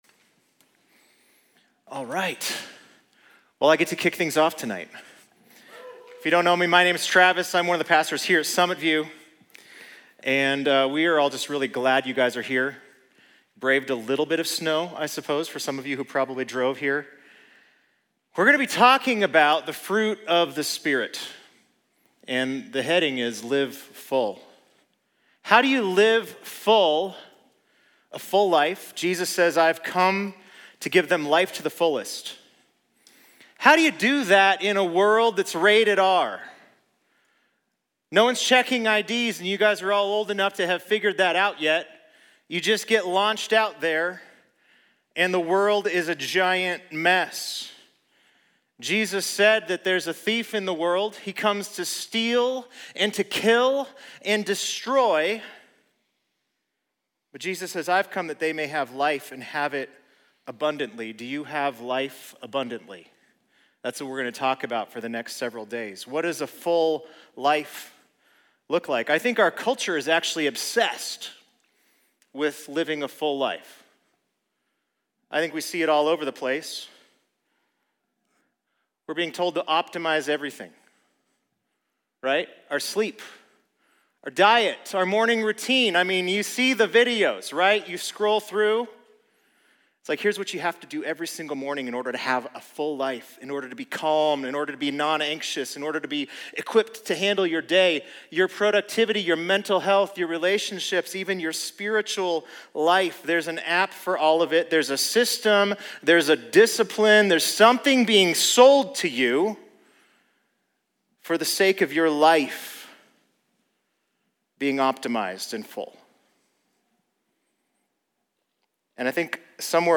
Series: Live Full: Winter College & Young Adults Conference 2026